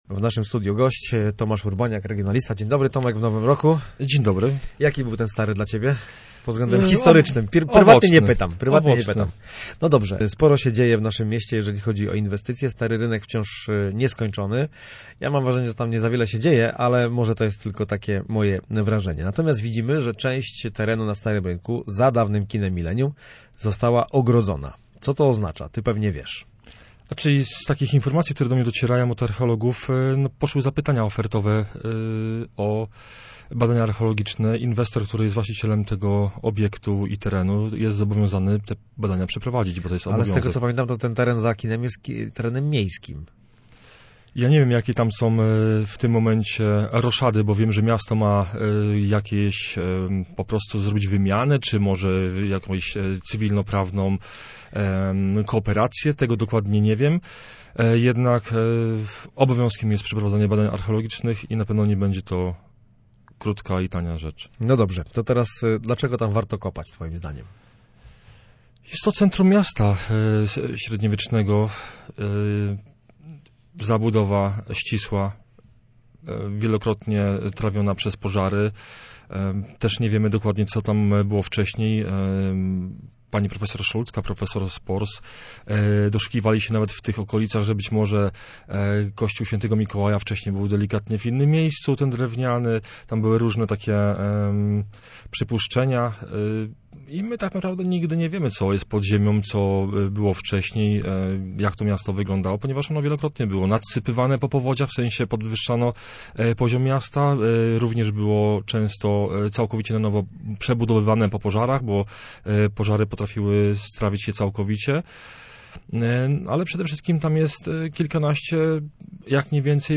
Rozmowy